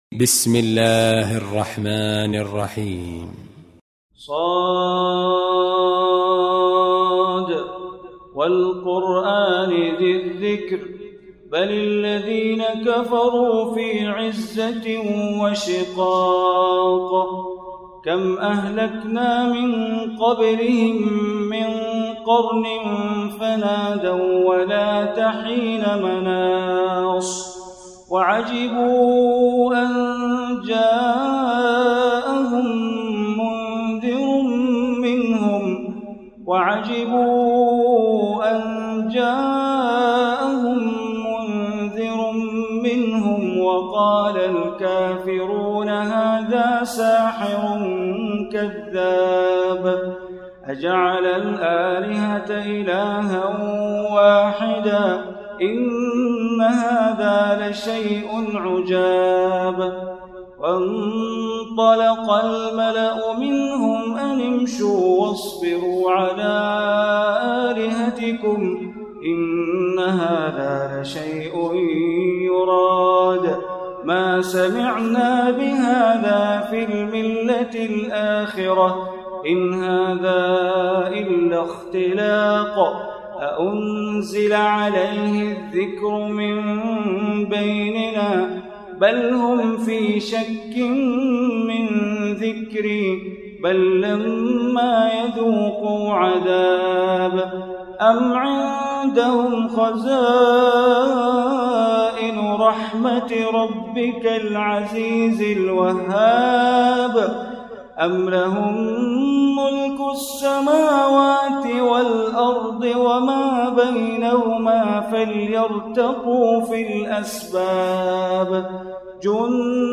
Surah Sad Recitation by Sheikh Bandar Baleela
Surah Sad, listen online mp3 tilawat / recitation in Arabic, recited by Imam e Kaaba Sheikh Bandar Baleela.